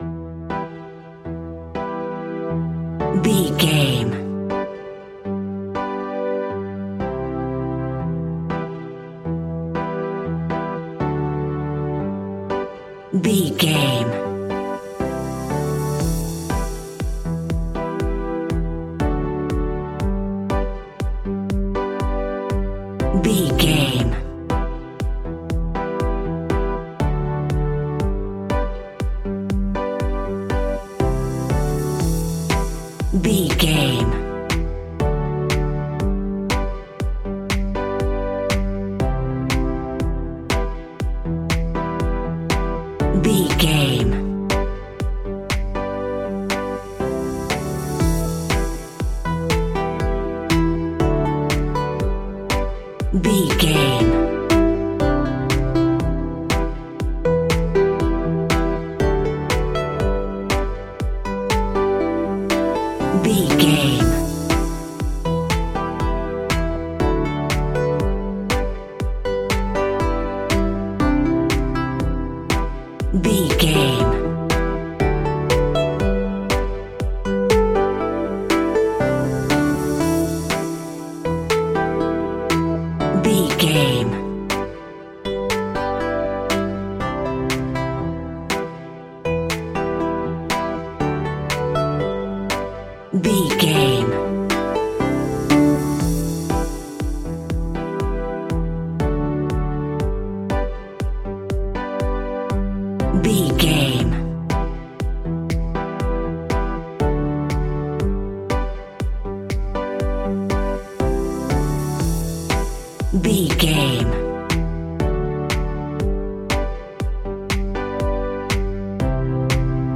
royalty free music
Aeolian/Minor
joyful
hopeful
synthesiser
drum machine
electric piano
acoustic guitar
electronic
synth leads
synth bass